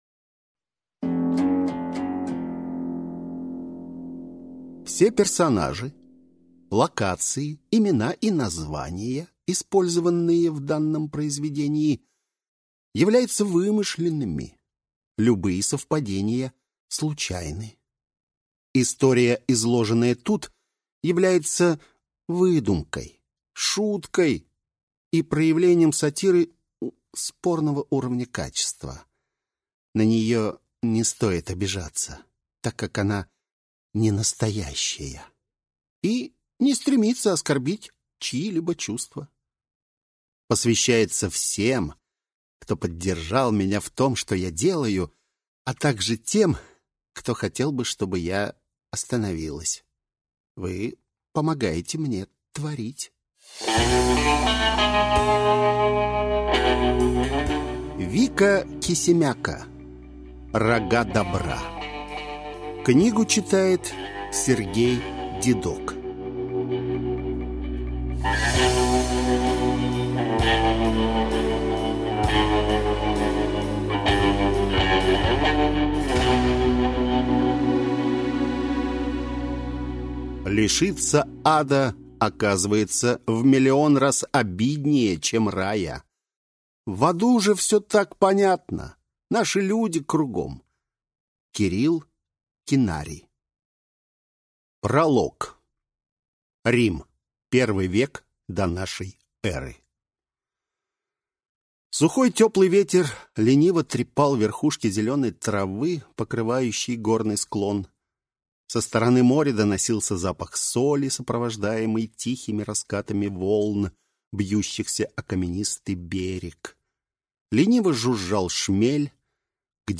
Аудиокнига Рога Добра | Библиотека аудиокниг